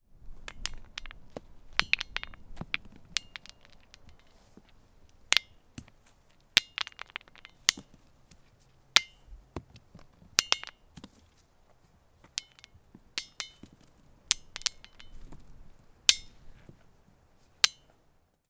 Type: Klangstein
Tonen er lys, og flere overtoner kan høres, men ingen veldig klar frekvens slår gjennom den noe metalliske klangen. Tonehøyden er den samme uansett hvor man slår.
Hør lyden av småstein kastet på Den klingende sten
Holden-lyd-smastein.mp3